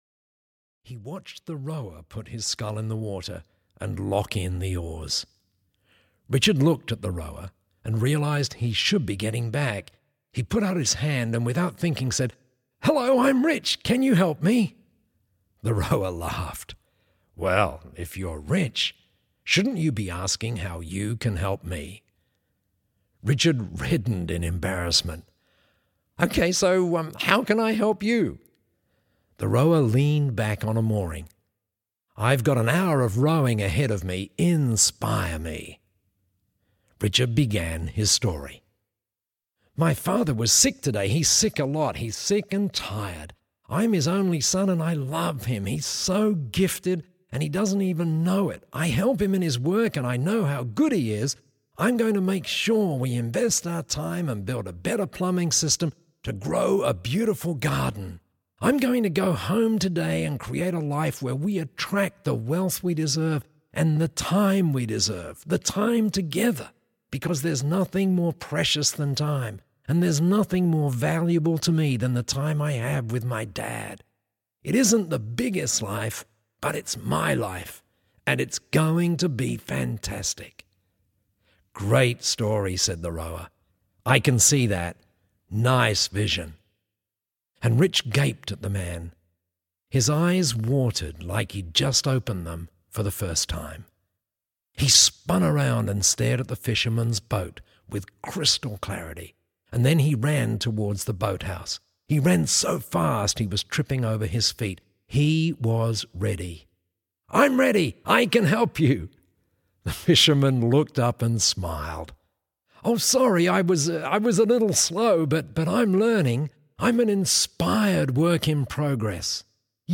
Audio knihaWink and Grow Rich 2 (EN)
Ukázka z knihy